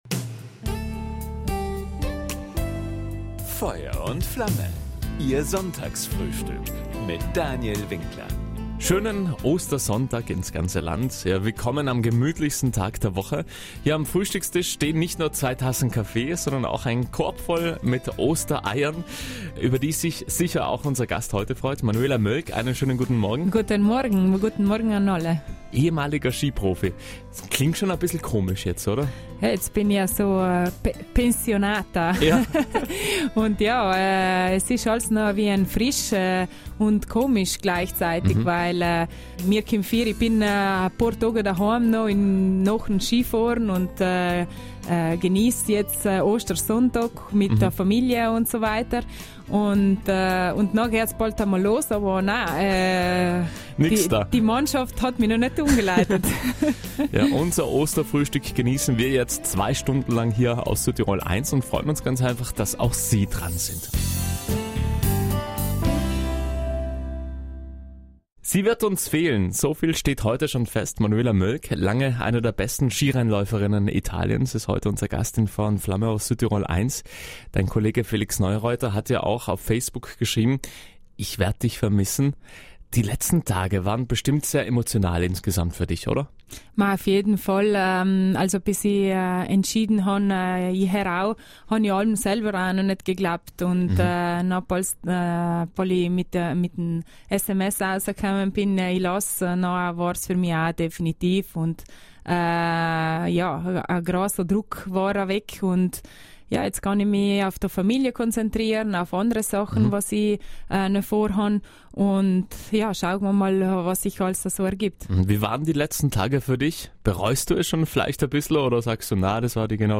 Beim Sonntagsfrühstück auf Südtirol 1 verrät Manuela Mölgg wie es jetzt weiter gehen soll, was sie sich von ihrer Zukunft erwartet und welche Erlebnisse sie als Ski-Profi ihr ganzes Leben nicht vergessen wird.